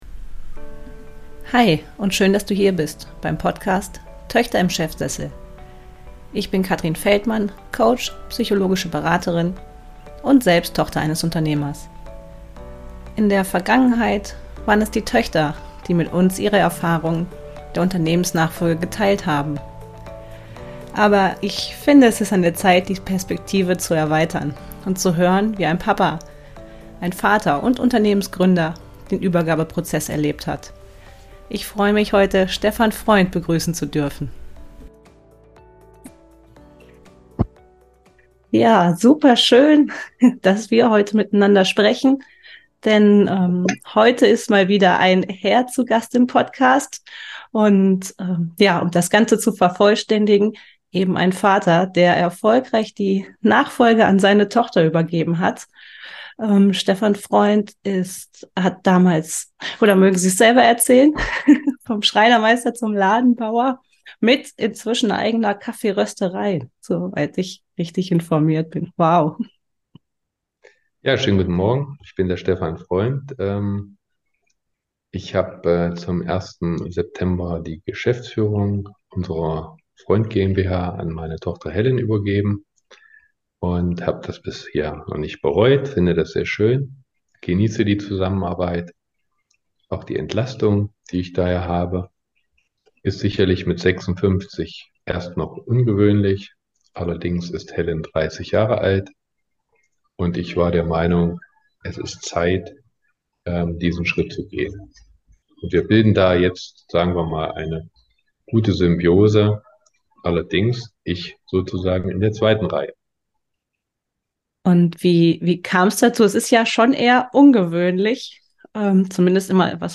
Perspektivwechsel – Interview